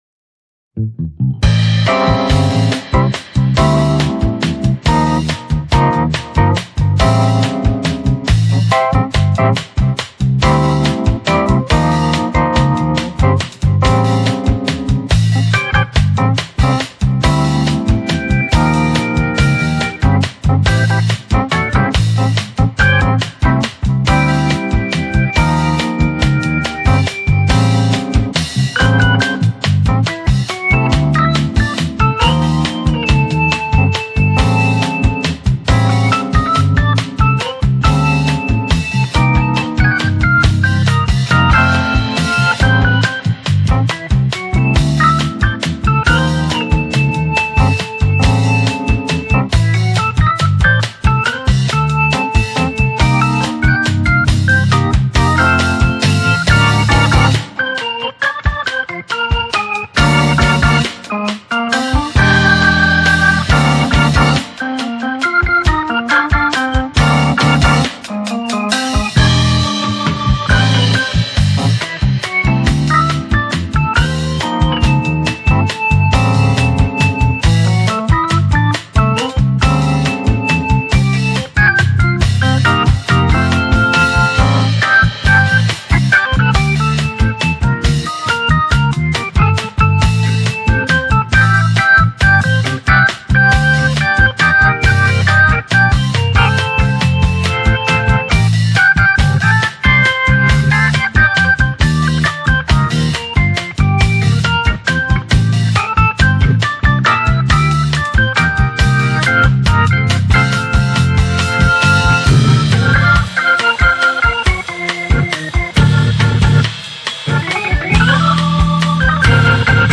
these are pretty lo-rez.